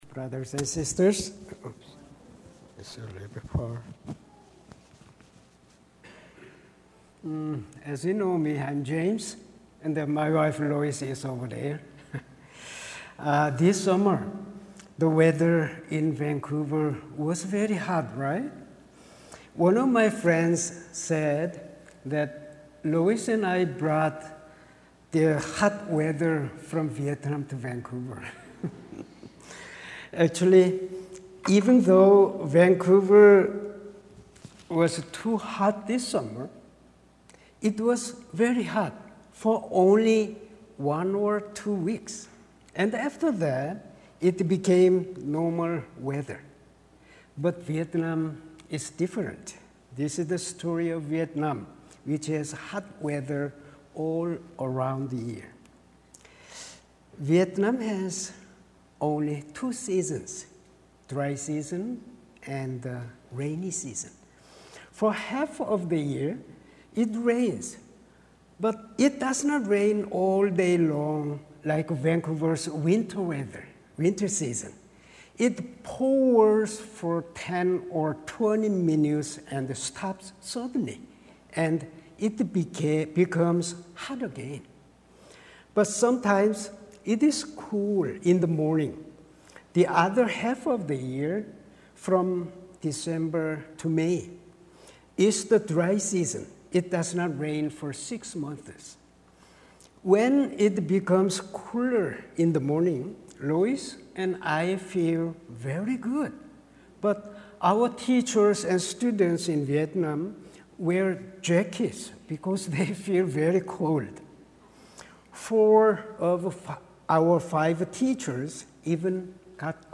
Sermons | Oakridge Baptist Church